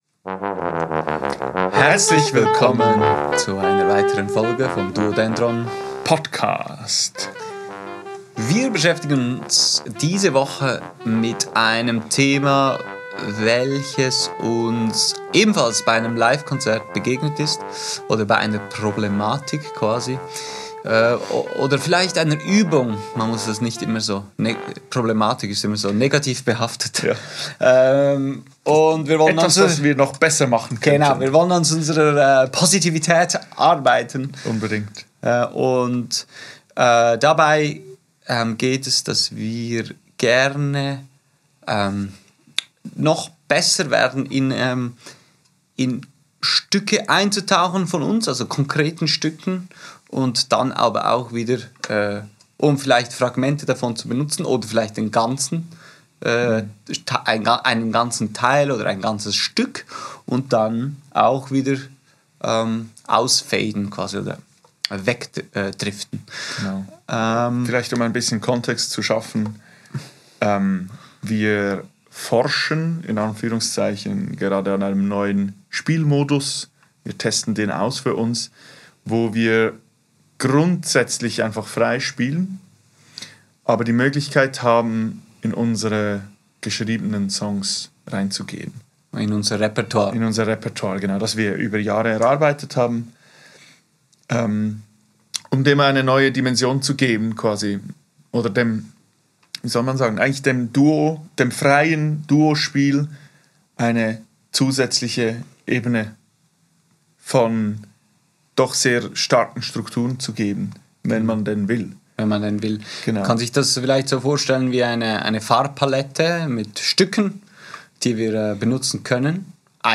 frei improvisieren